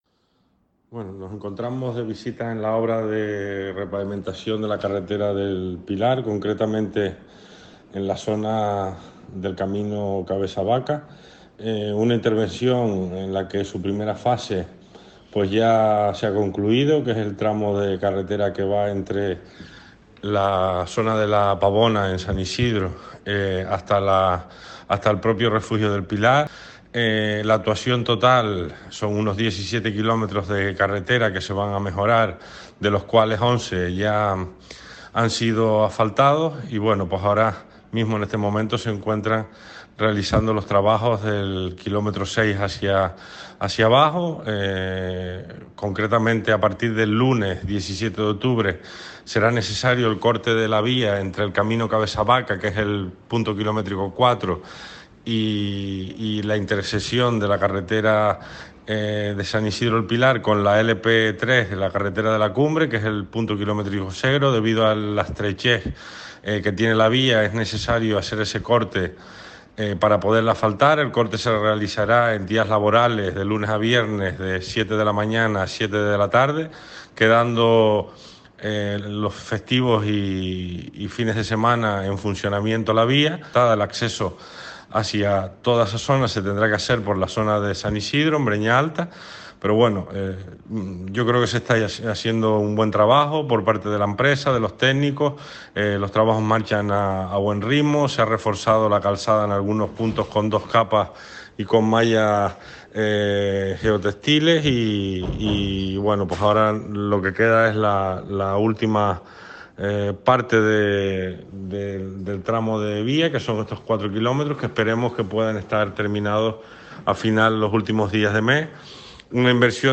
El vicepresidente del Cabildo y consejero de Infraestructuras, Borja Perdomo, tras visitar las obras que ejecuta la empresa Asfaltos Palmeros SL, ha destacado el buen ritmo al que marchan los trabajos, y avanzó que para la conclusión de la intervención será necesario proceder al cierre temporal de la vía entre el camino Cabeza de Vaca y la intersección con la carretera de La Cumbre, a partir del próximo lunes, 17 de octubre, hasta el 31 del mismo mes, entre las 7:00 a las 19:00 horas, los días laborables.
Declaraciones audio Borja Perdomo_0.mp3